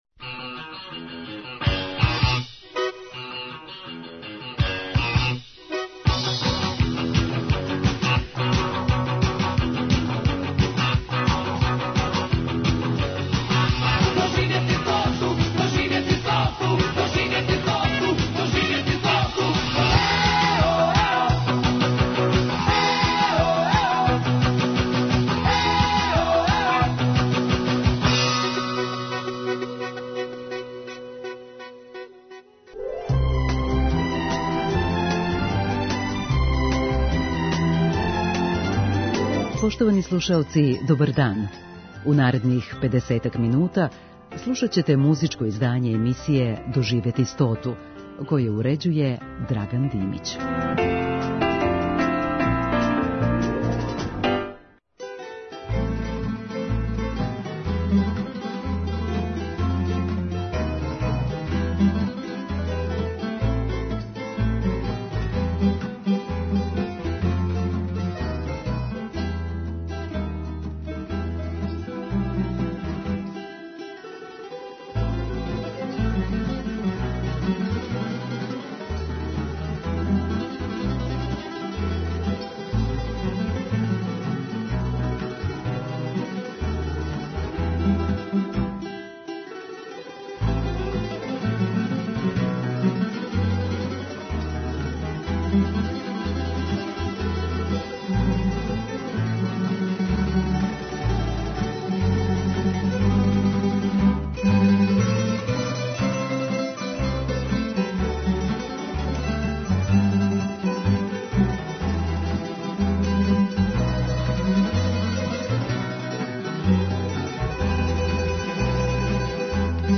У оквиру летње програмске шеме, слушаћете музичко издање емисије „Доживети стоту“ са одабраним евергрин песмама.